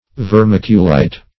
Vermiculite \Ver*mic"u*lite\, n. [L. vermiculus, dim. of vermis